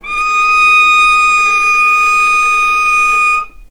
healing-soundscapes/Sound Banks/HSS_OP_Pack/Strings/cello/ord/vc-D#6-mf.AIF at 48f255e0b41e8171d9280be2389d1ef0a439d660
vc-D#6-mf.AIF